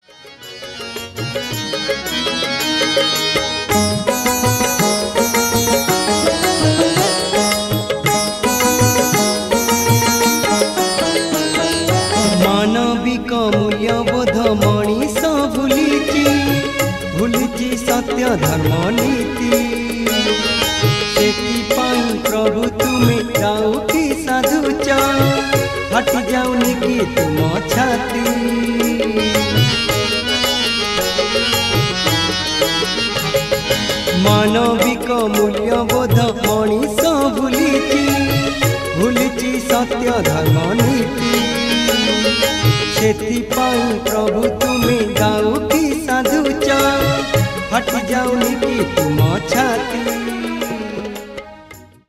Odia bhajan ringtone free download